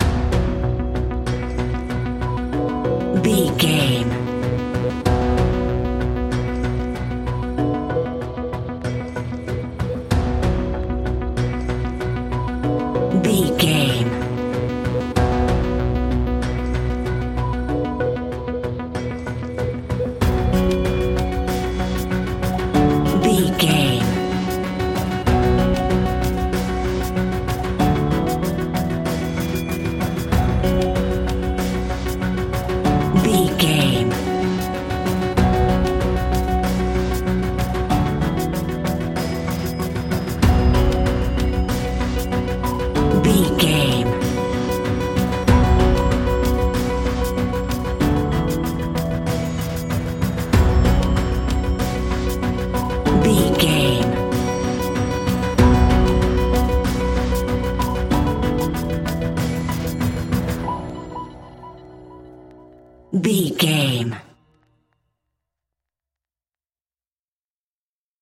In-crescendo
Aeolian/Minor
scary
ominous
eerie
suspenseful
electronic music
Horror Pads
Horror Synths